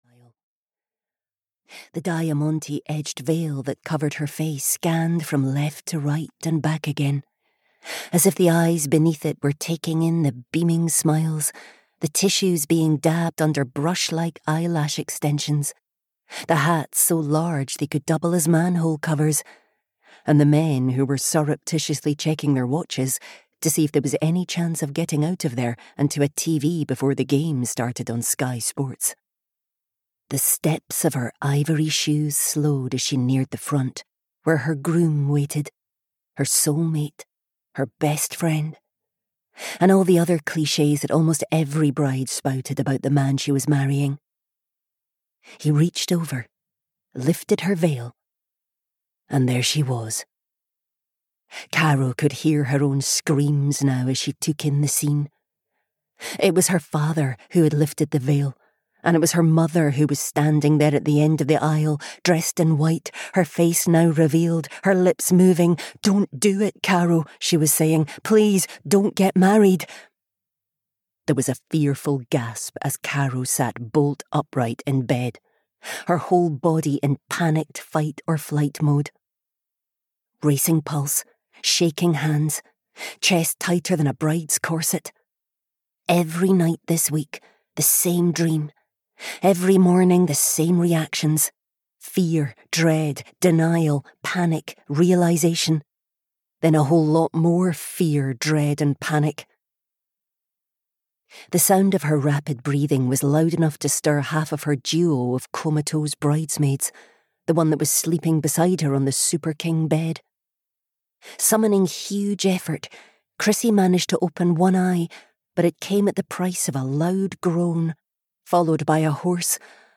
The Last Day of Winter (EN) audiokniha
Ukázka z knihy